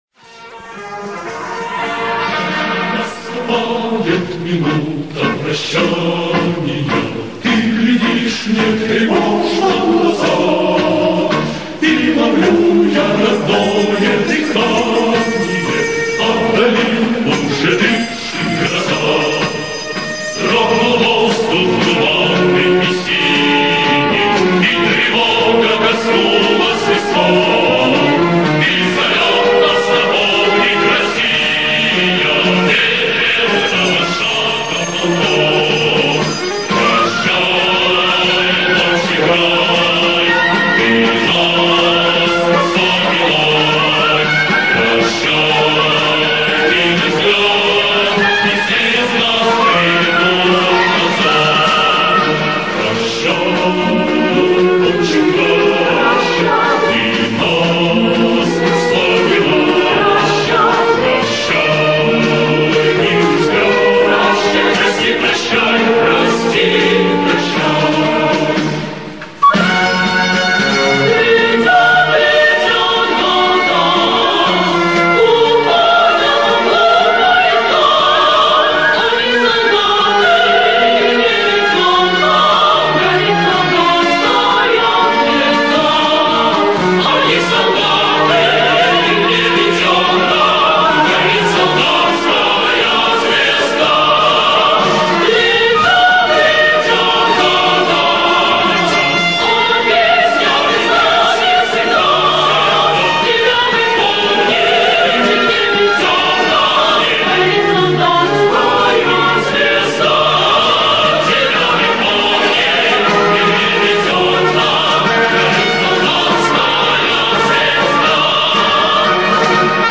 Марш «Прощание славянки» В.И. Агапкина на слова В.Я. Лазарева